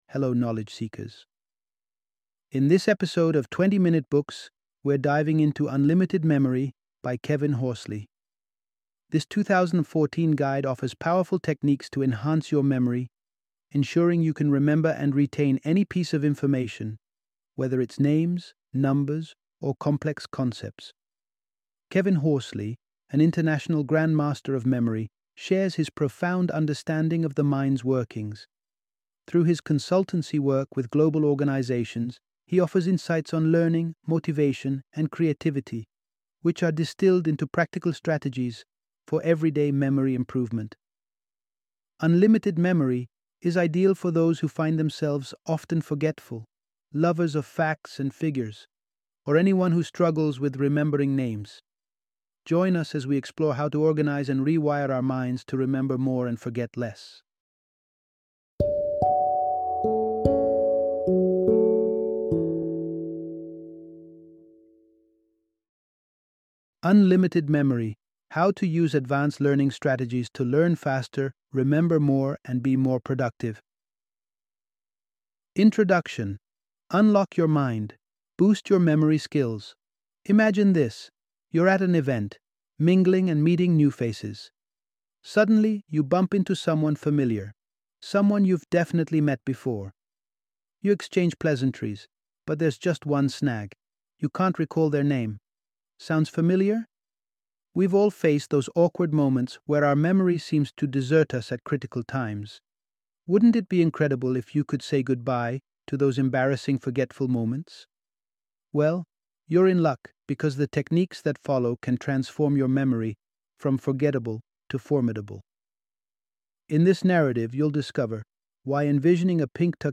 Unlimited Memory - Audiobook Summary